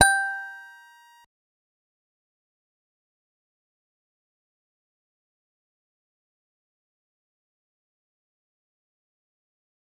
G_Musicbox-G6-mf.wav